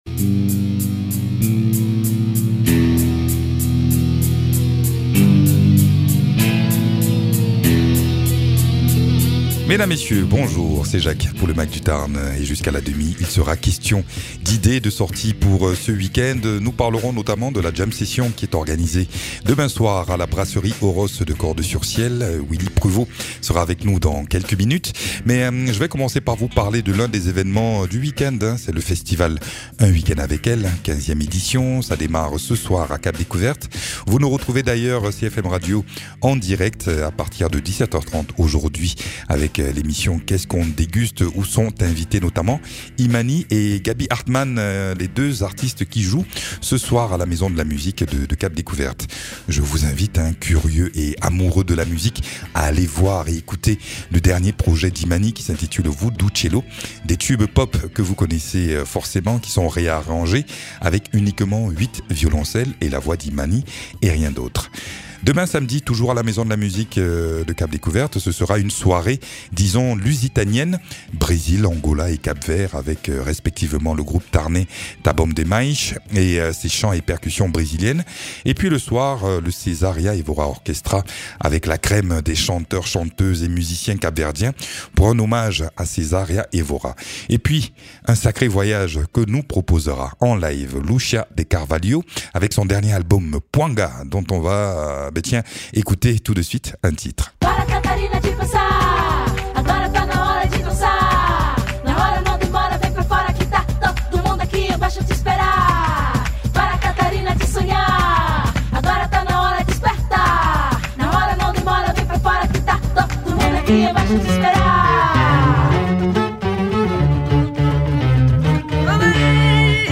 musicien